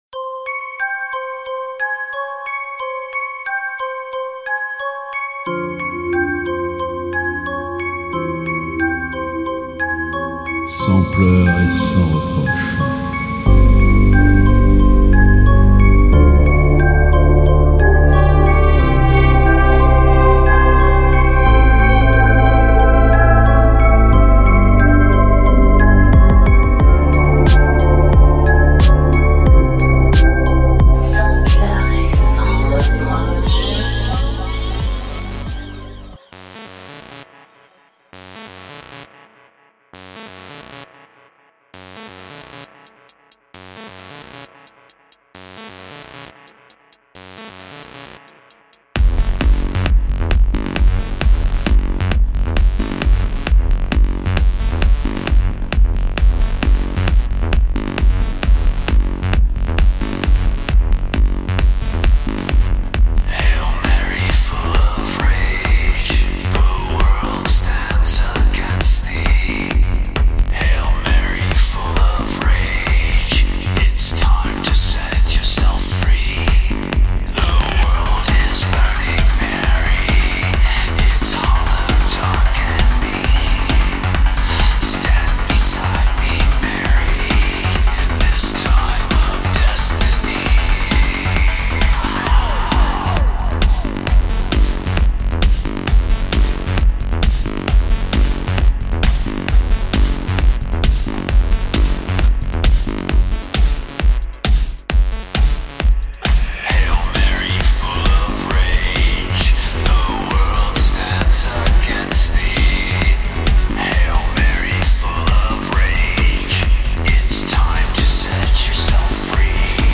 Radio broadcast)Playlist N° 1367
EBM - DARKTECHNO - INDUSTRIEL & RELATED MUSIC